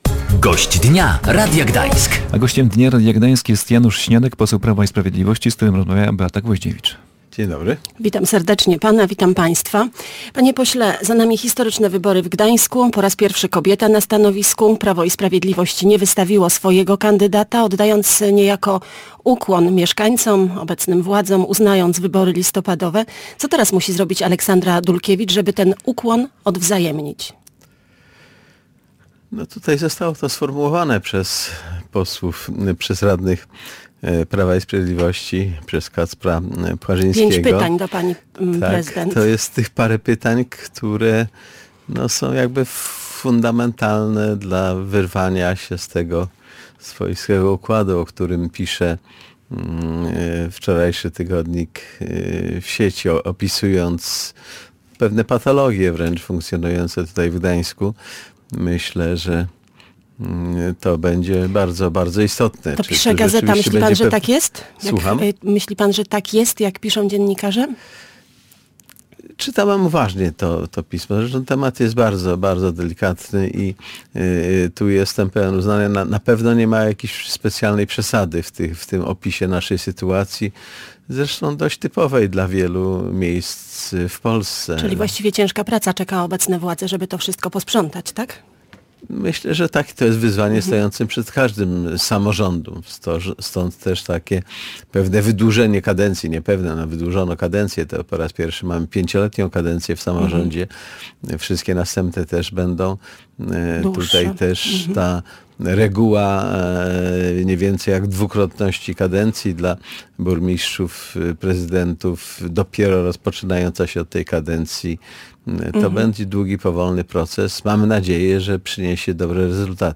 Polityk skomentował też wyniki przedterminowych wyborów na prezydenta Gdańska. Janusz Śniadek był Gościem Dnia Radia Gdańsk.